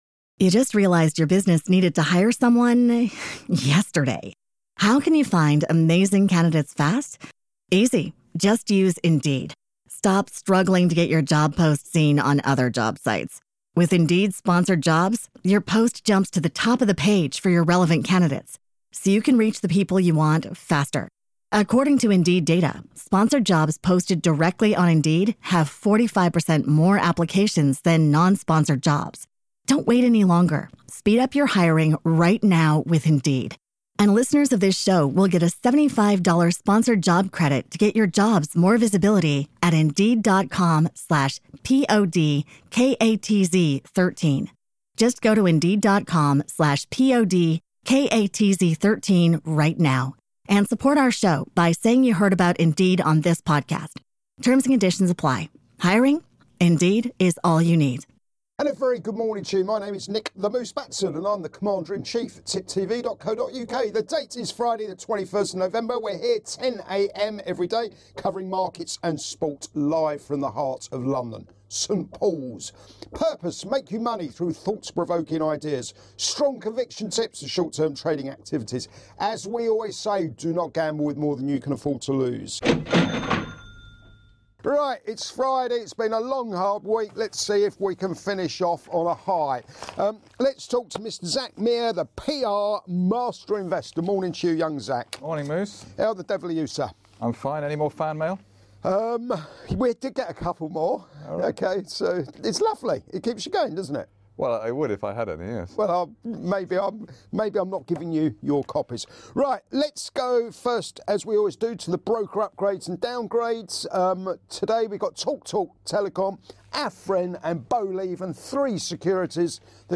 Live Market Round Up & Soapbox Thoughts